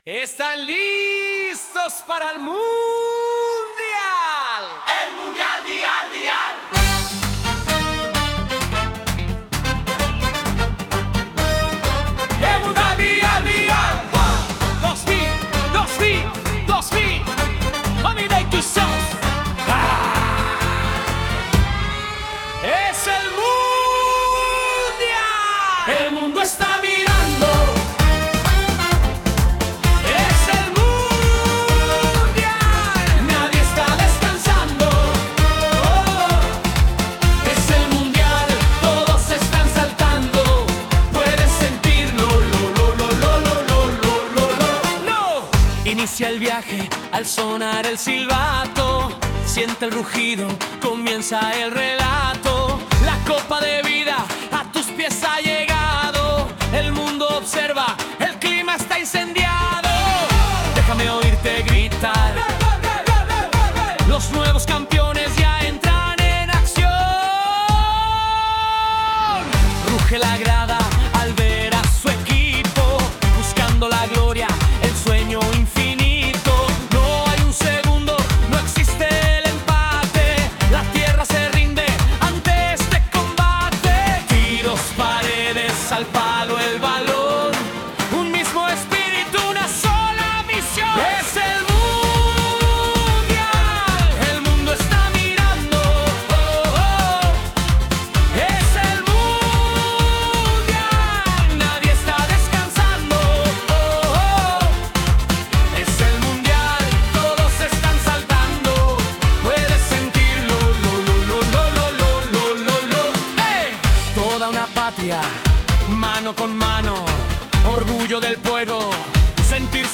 un buen rítmo latino.